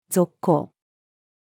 続行-female.mp3